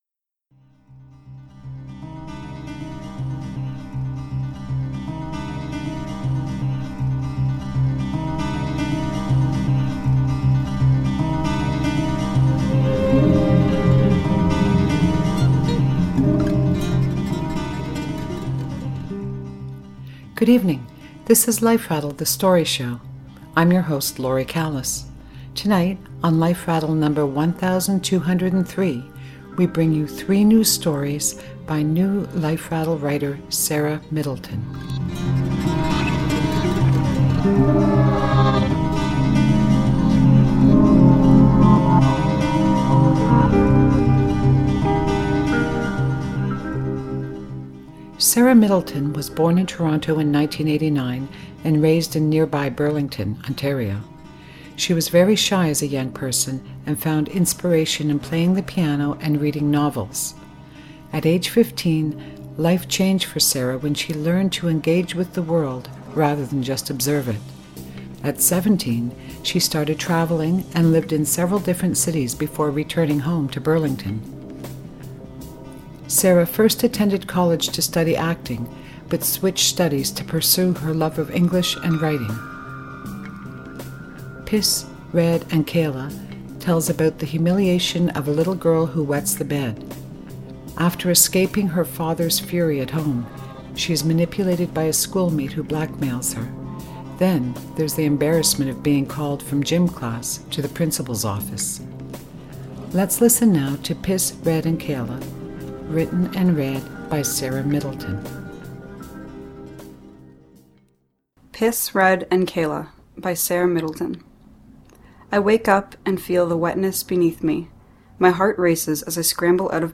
features three new stories by new Life Rattle writer